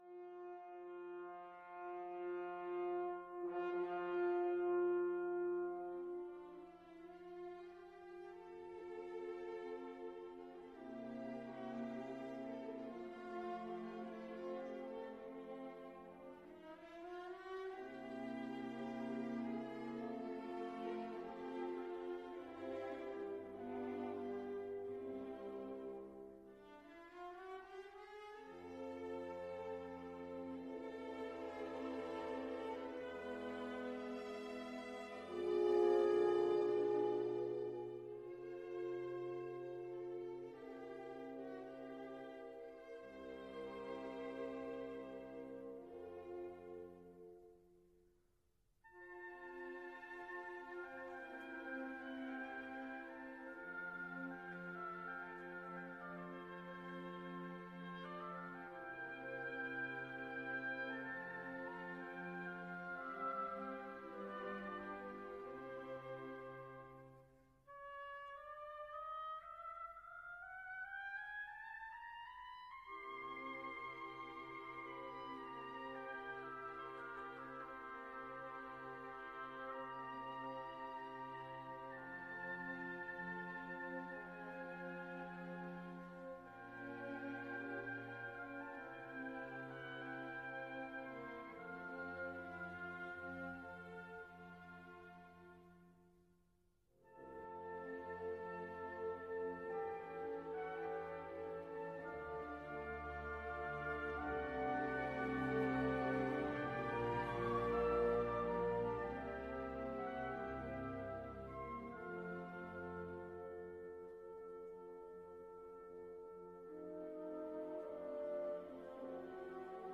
Symphonie n°2 en C Majeur, J51